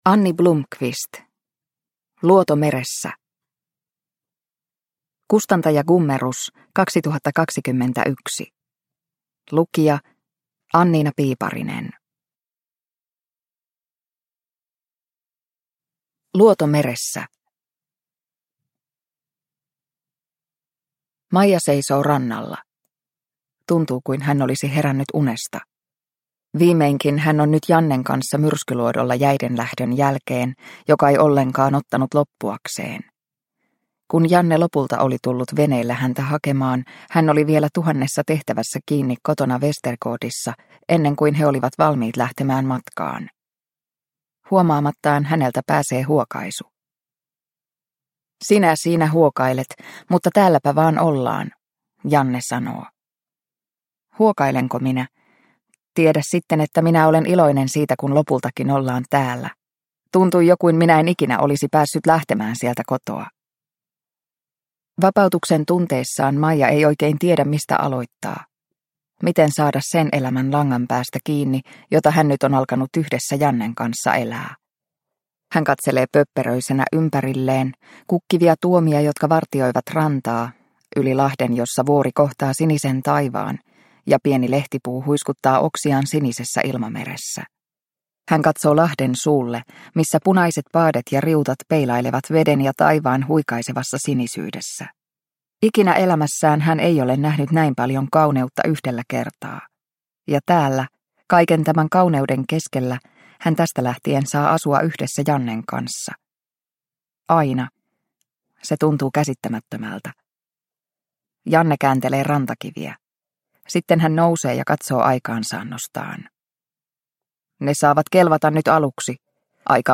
Luoto meressä – Ljudbok – Laddas ner